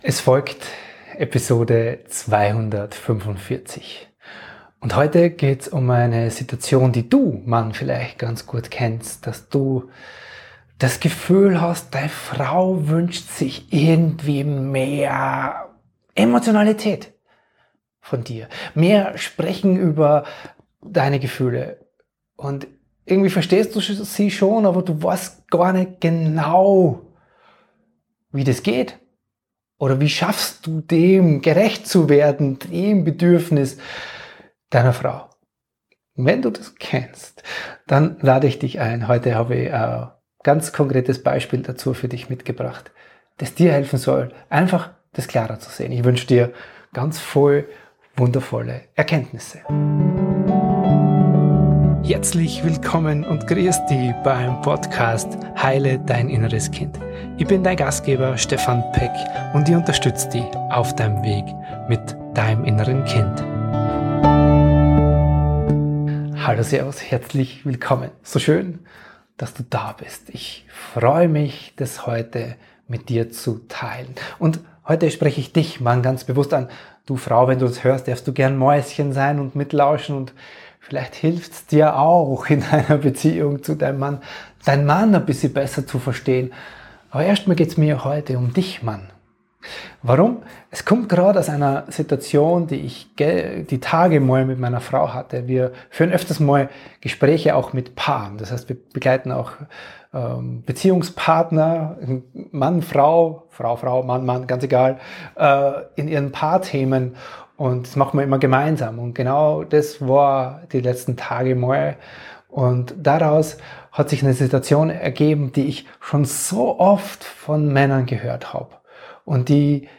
(Hinweis: Bitte entschuldige die etwas schlechtere Tonqualität!!)